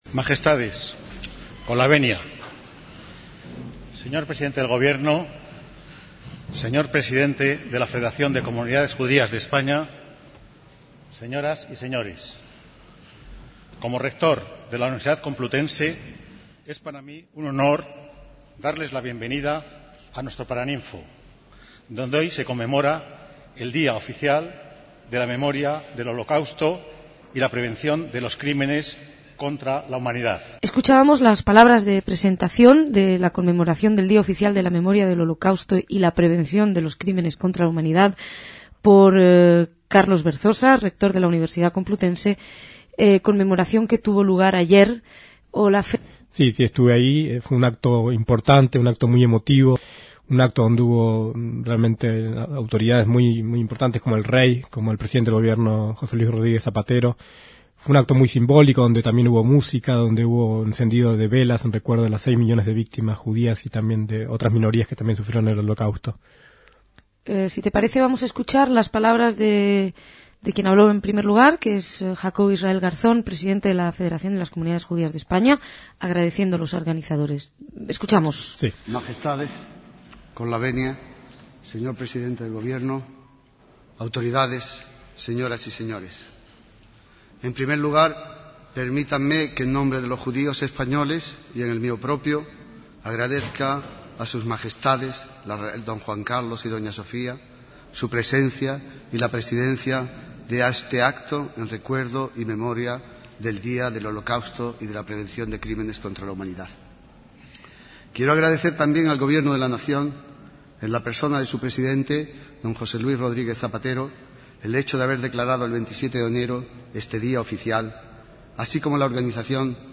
DECÍAMOS AYER (27/1/2006) - Uno de los primeros actos oficiales en conmemoración de las víctimas del Holocausto tuvieron lugar a inicios de 2006, contando con la presencia del Jefe de Estado y el de Gobierno, de cuyos discursos se emitieron algunos fragmentos destacados.